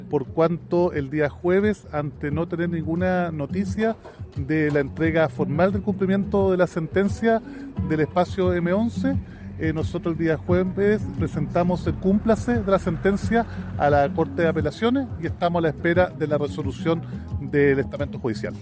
Así las cosas, el plazo para devolver el espacio donde está el Complejo M11 venció el pasado 7 de abril, por tanto, la Municipalidad solicitó que se cumpla lo ordenado por la Corte Suprema, señaló el alcalde de Temuco, Roberto Neira.